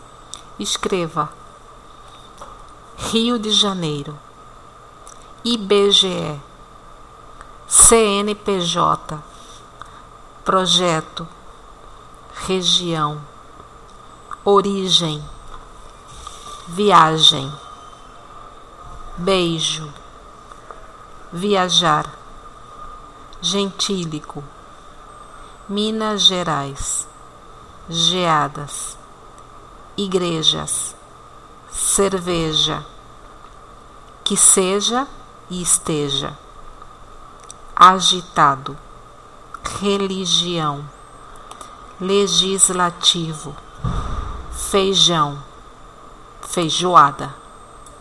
ditado palavras pronùncia fonema alveopalatal sonoro.mp3